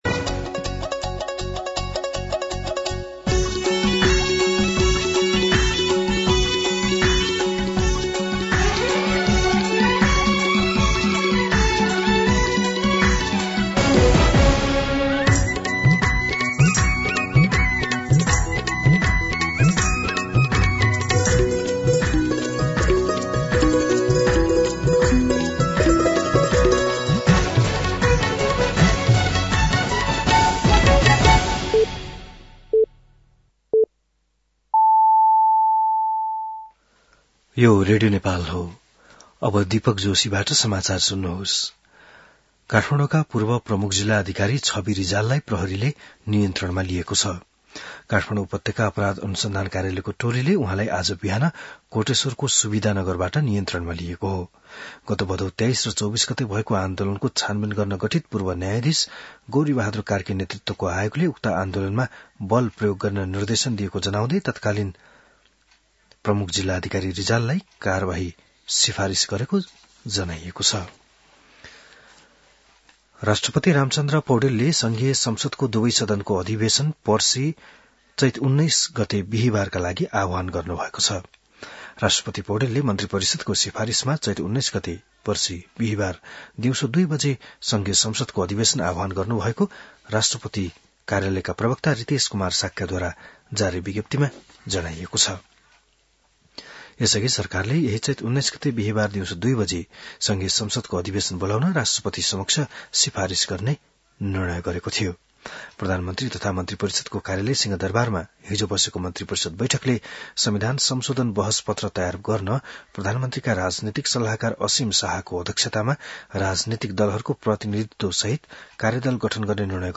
An online outlet of Nepal's national radio broadcaster
बिहान ११ बजेको नेपाली समाचार : १७ चैत , २०८२